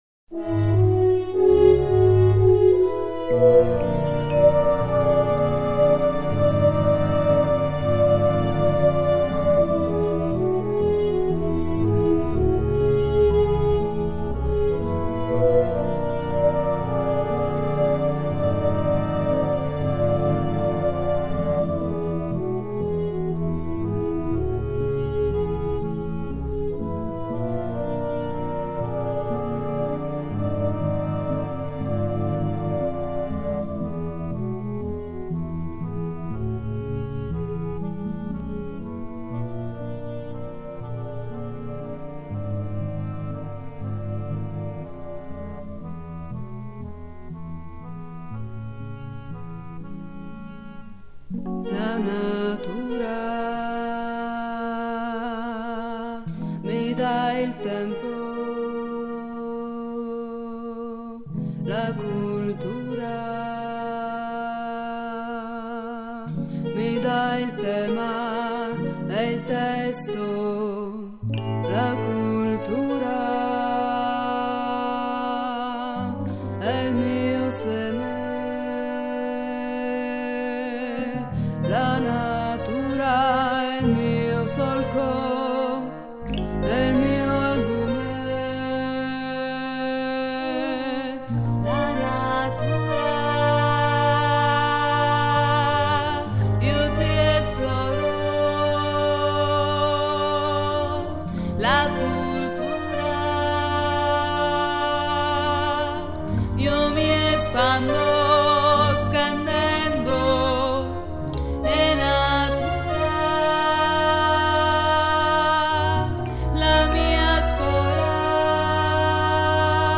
Melologo e pantomima musicale